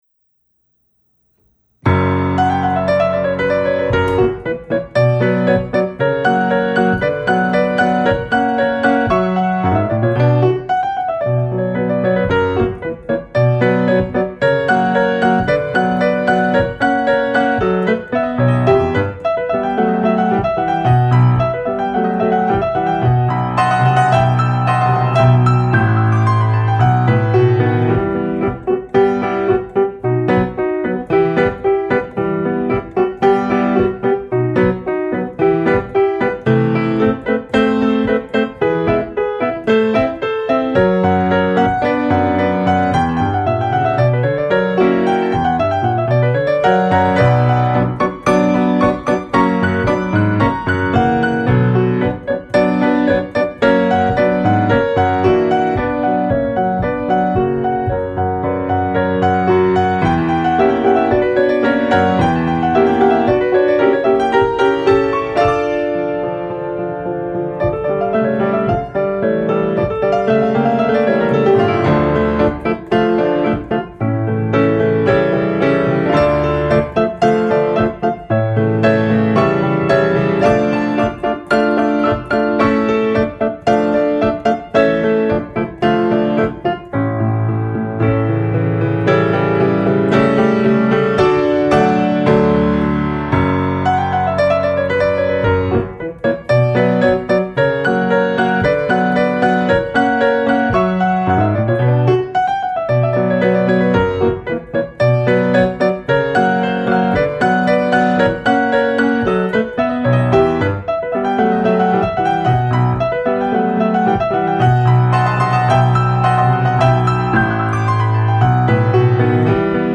As is common with scherzos, this is written in a rounded binary form.  It has an A section, in B minor, which is repeated.  It modulates to the relative major for the B section, which then returns to A, and then the whole B-A section is repeated.